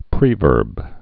(prēvûrb)